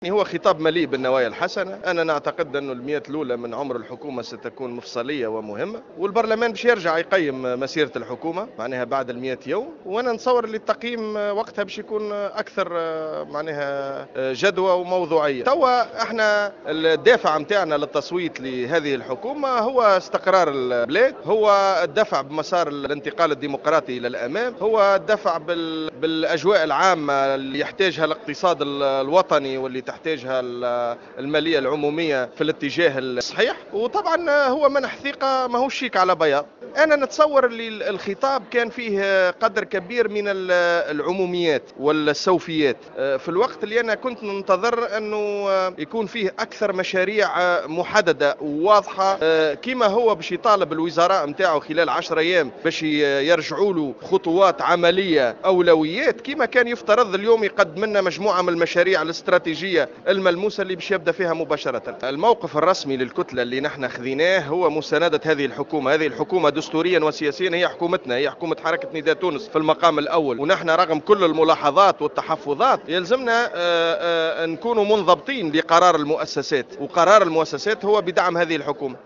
اعتبر النائب عن حزب نداء تونس خالد شوكات في تصريح لمراسلة جوهرة أف أم اليوم الاربعاء 4 فيفري 2015 أن خطاب رئيس الحكومة الحبيب الصيد مليء بالنوايا الحسنة وفق قوله.